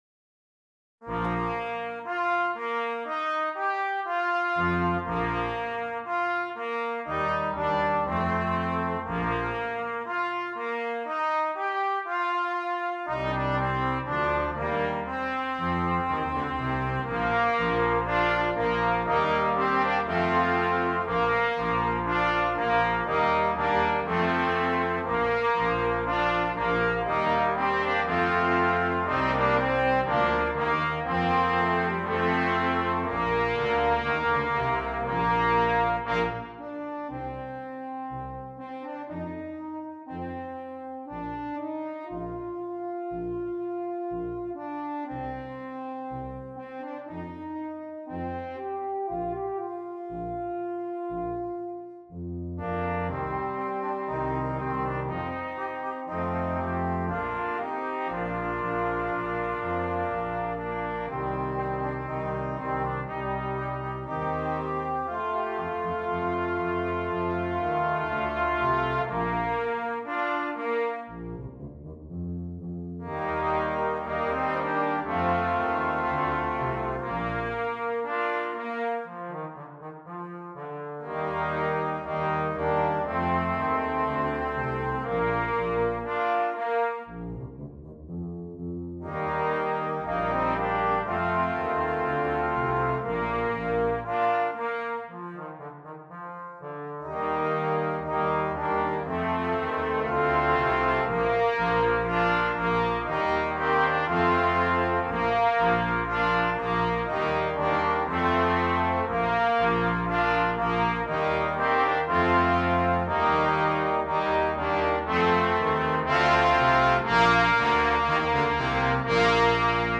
Brass Quintet
Regal and heroic themes are contrasted by lyrical playing.